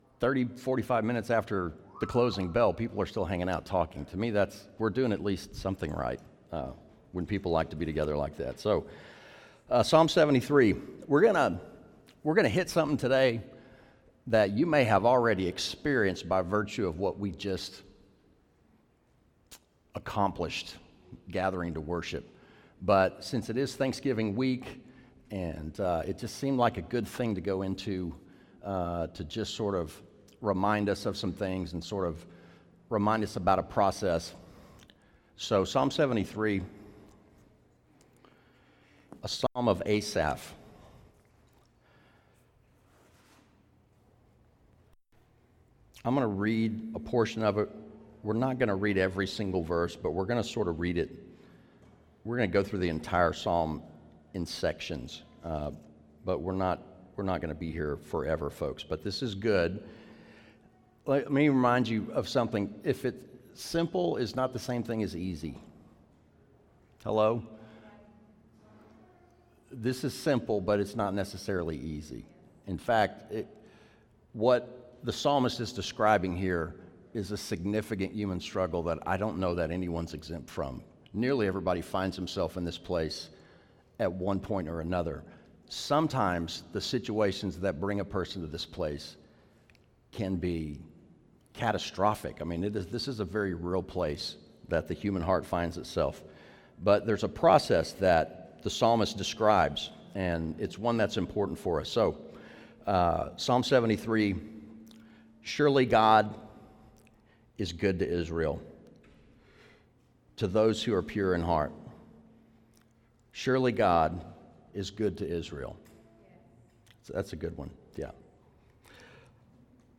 The Mic cutout for about a minute in the middle of the sermon cause the batteries died.